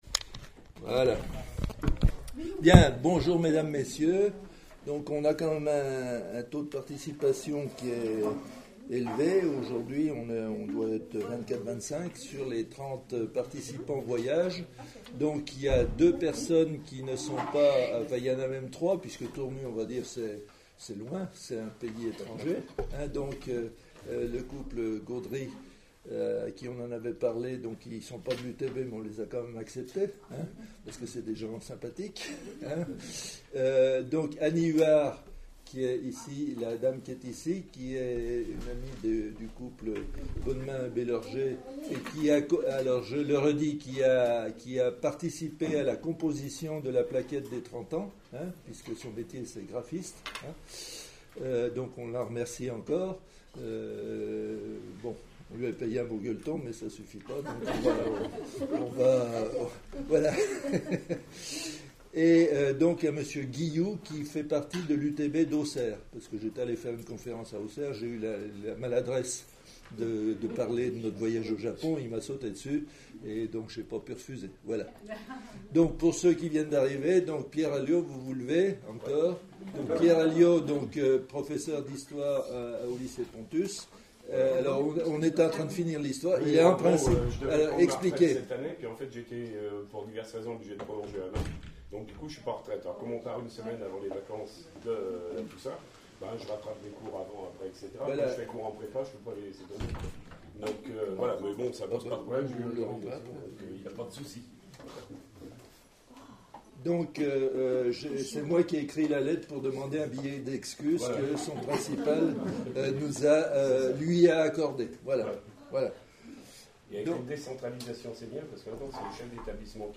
Enregistrement réunion du 16 septembre 2015